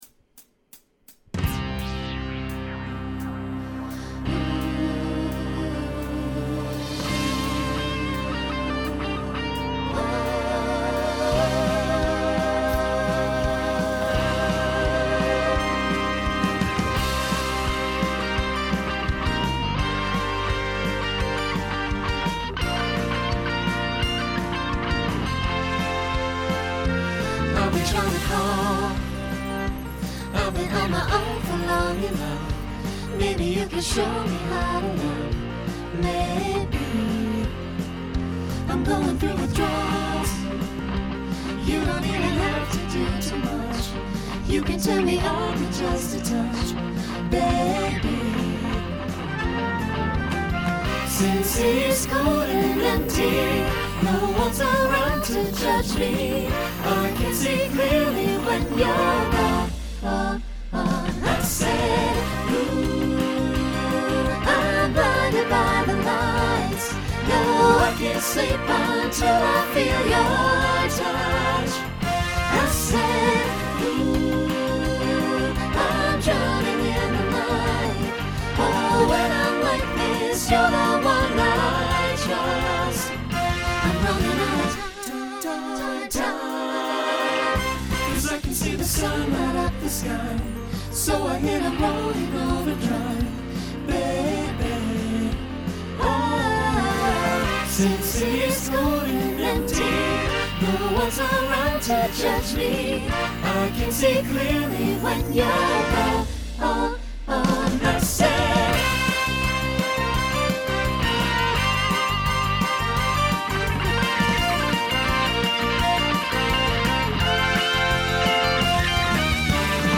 Genre Rock Instrumental combo
Opener Voicing SATB